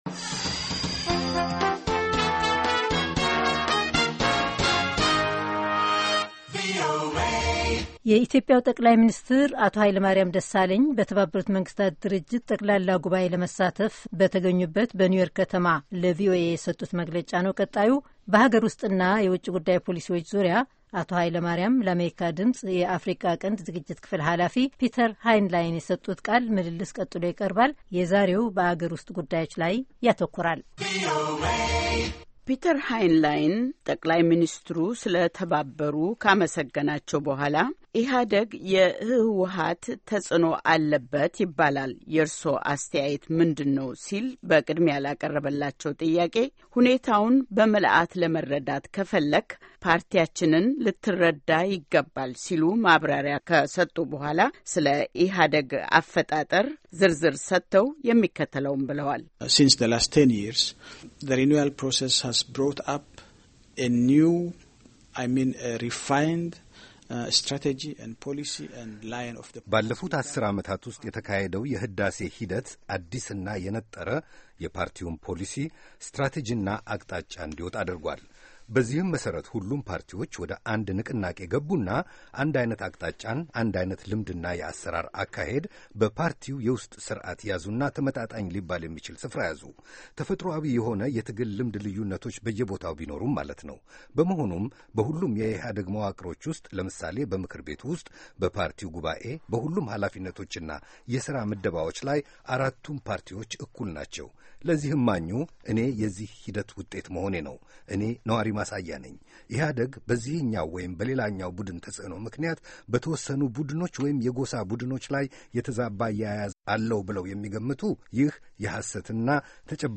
Amharic report.